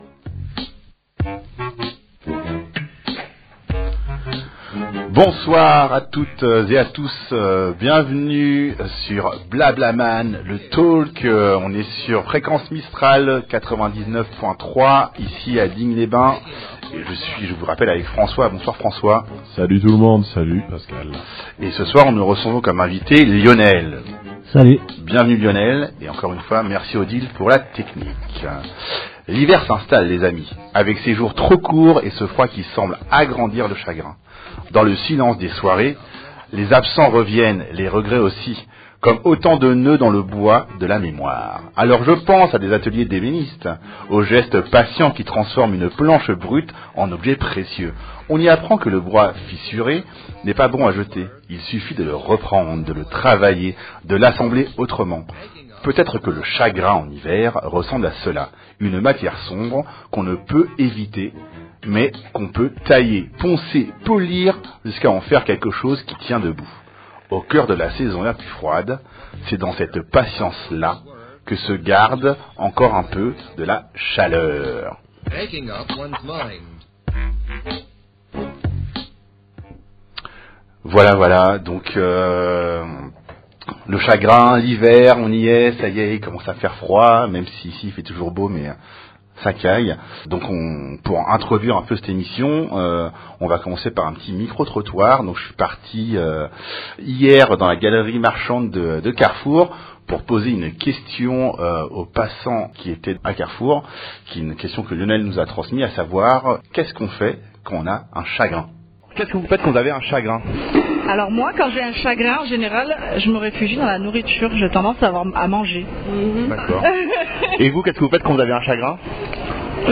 Mercredi 26 Novembre 2025 BlaBlaMan, c’est le talk qui donne la parole aux hommes pour évoquer les transformations liées aux ruptures que nous traversons dans la vie.